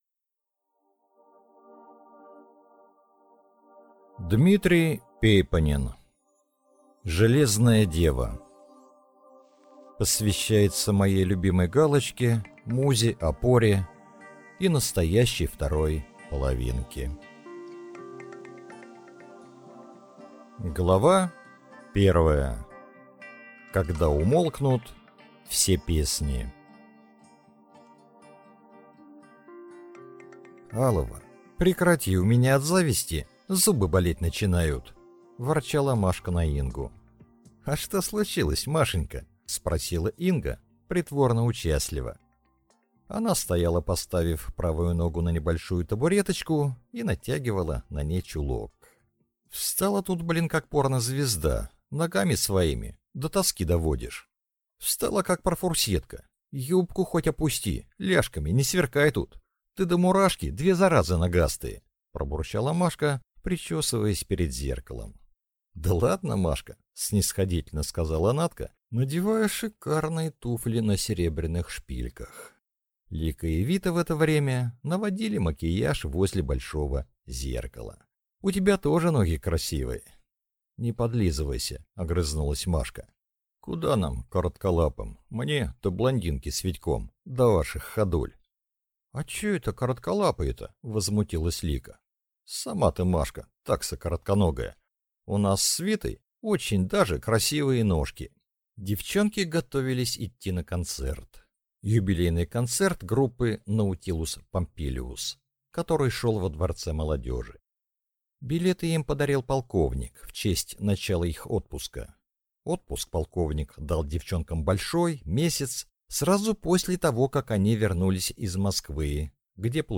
Аудиокнига Железная Дева | Библиотека аудиокниг
Прослушать и бесплатно скачать фрагмент аудиокниги